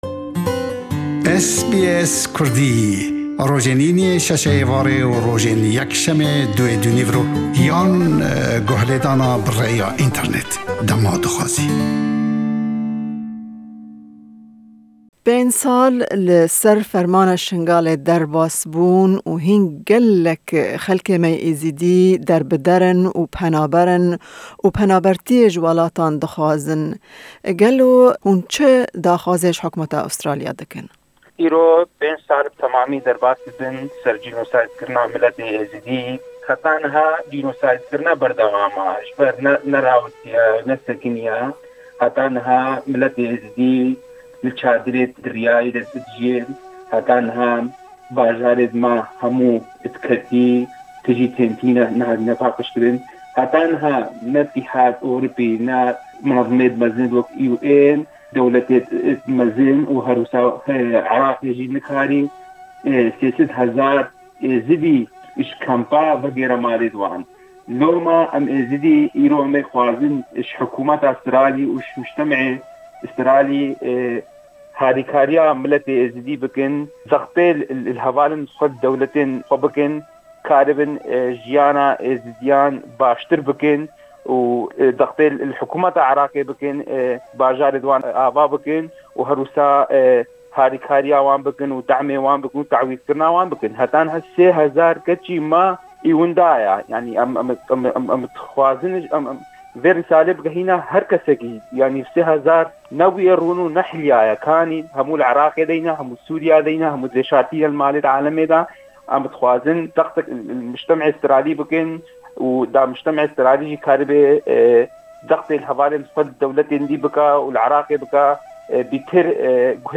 Em bi jimareke ji endamên civaka Êzîdî re axifîn.